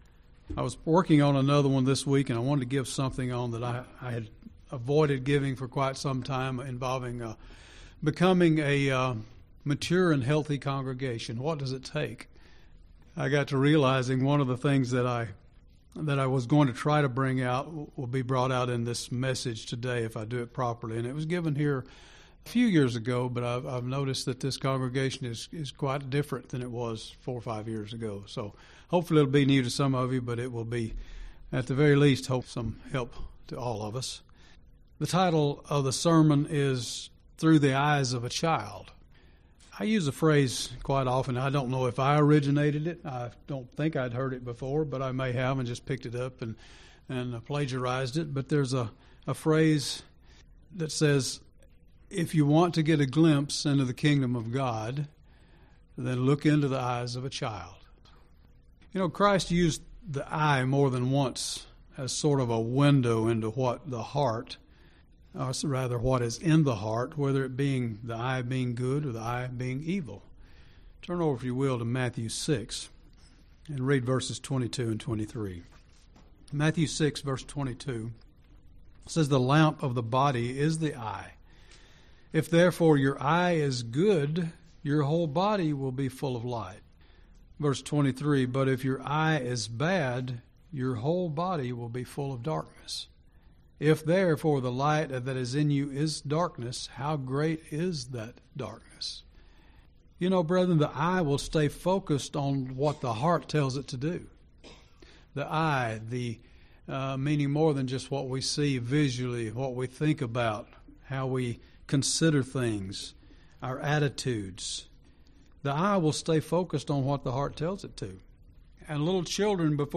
(Matt. 18:2-4) How do we humble ourselves as little children? This sermon illustrates several traits of little children that we must build in our lives through the power of God's Holy Spirit, if we are to inherit His Kingdom.